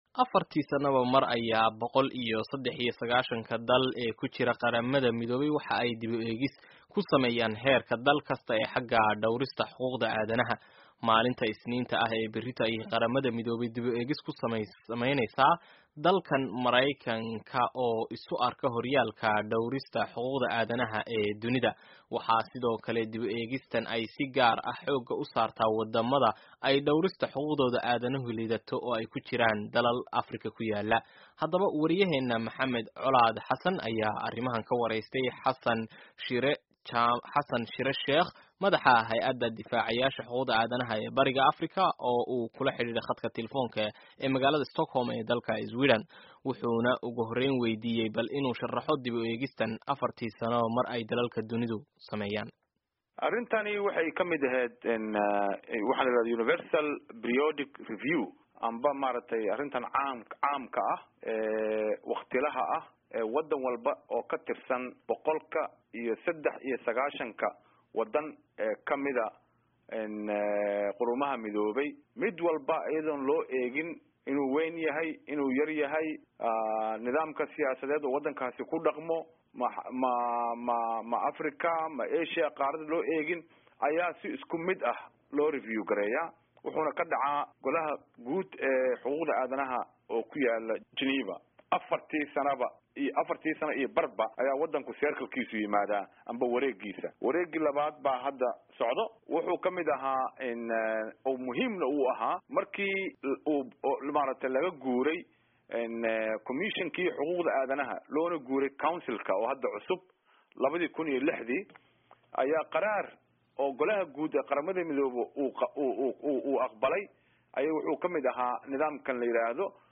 Wareysi iyo Falanqeyn ku aadan xuquuqda aadanaha